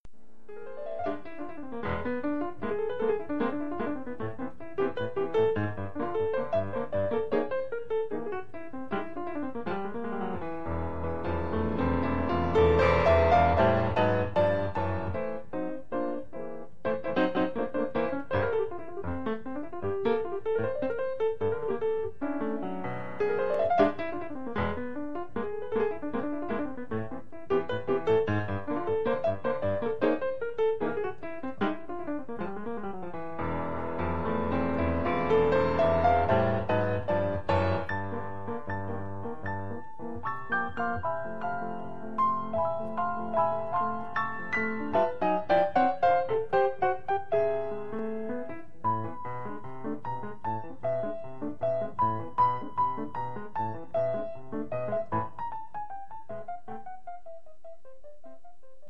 piano e voce
uno stupendo reprise per solo piano